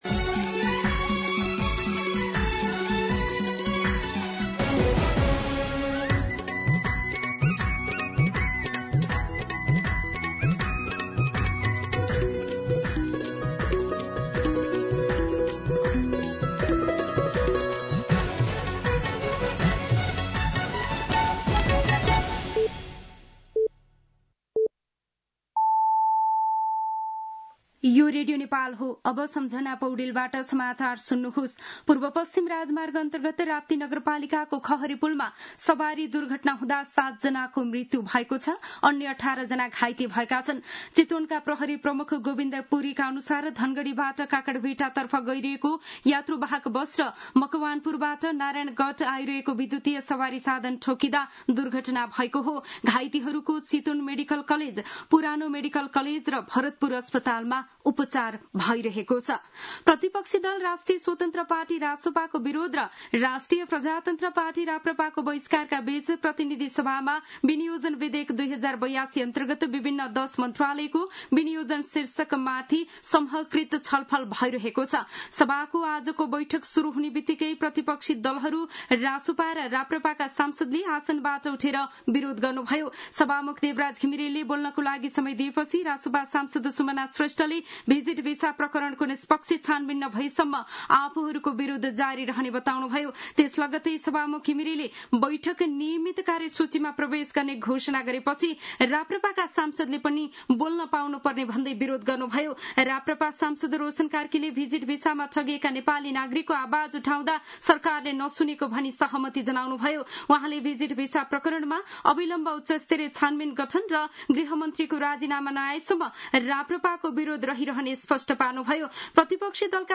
दिउँसो १ बजेको नेपाली समाचार : ७ असार , २०८२
1-pm-Nepali-News-3.mp3